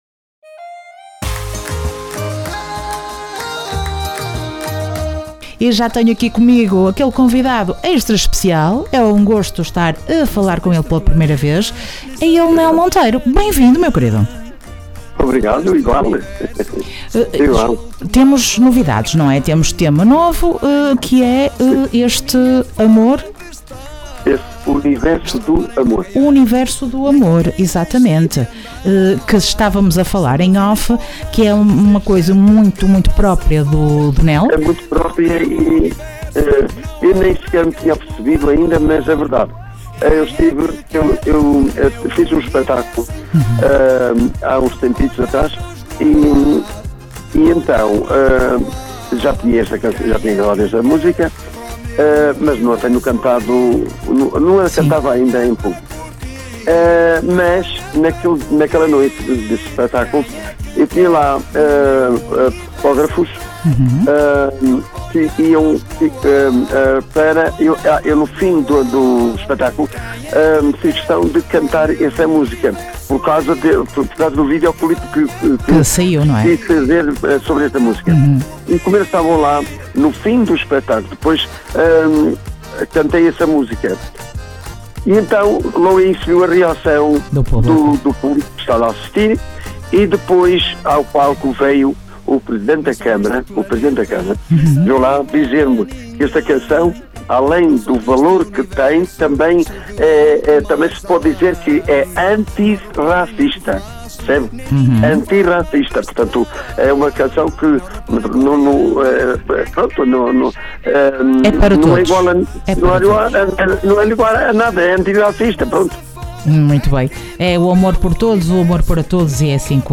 Entrevista Nel Monteiro dia 05 de Setembro